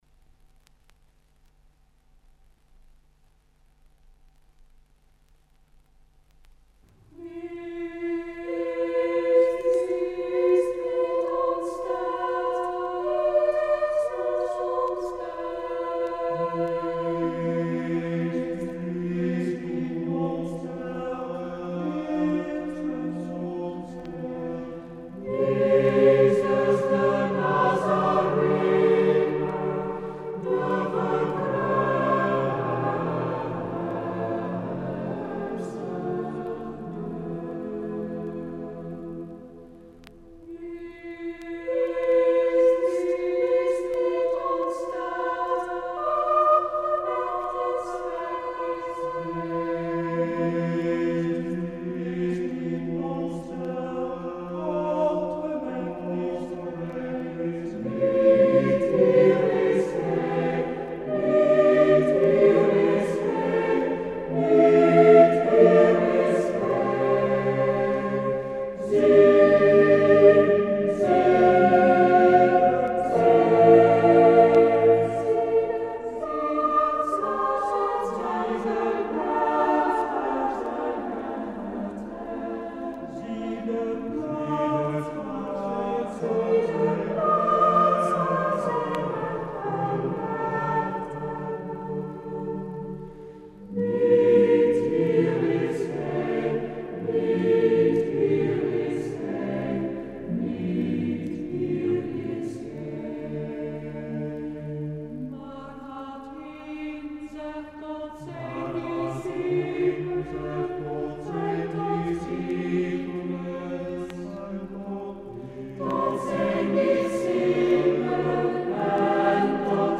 Een bijzonderheid tijdens het concert is het motet